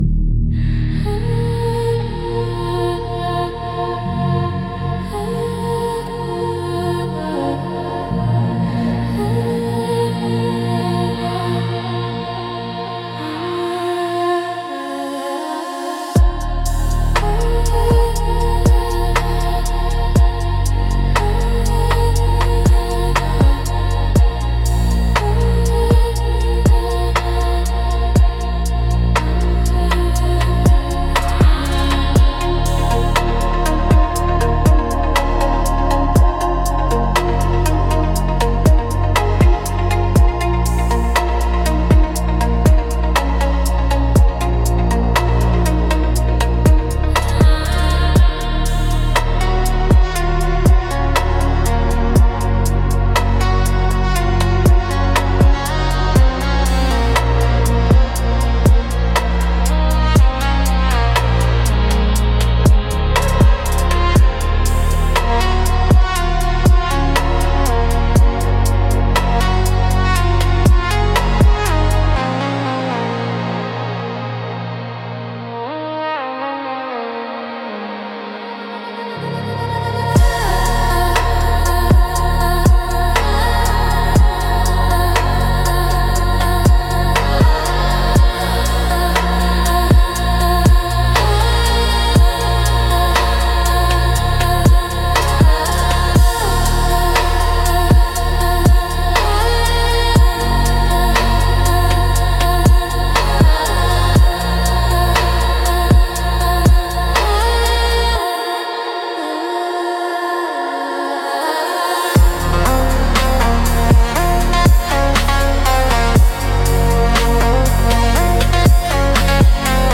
Instrumental - Distant Memory - 4.05